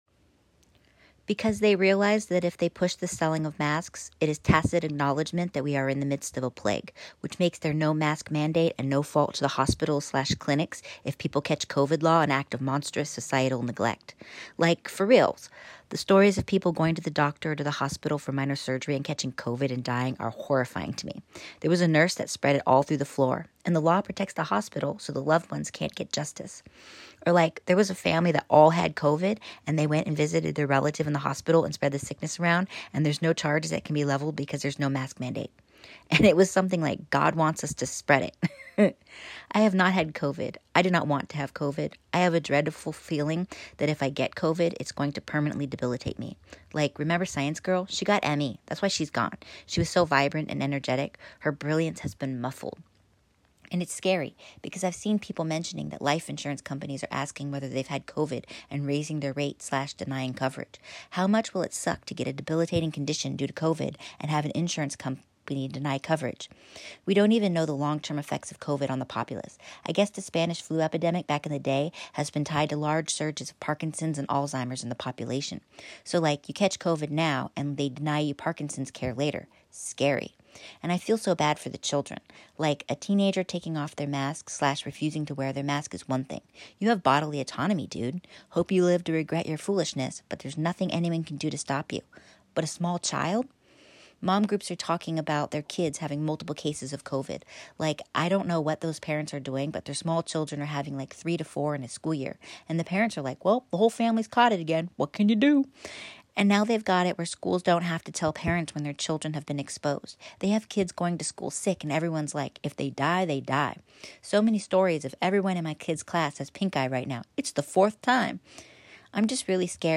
(The audio is me reading my Tweet-thread response.)